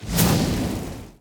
Fireball 1.wav